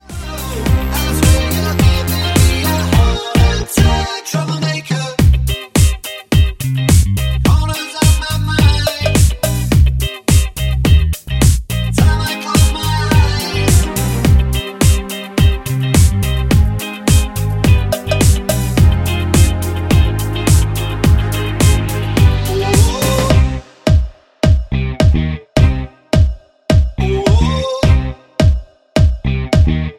Cm
MPEG 1 Layer 3 (Stereo)
Backing track Karaoke
Pop, 2010s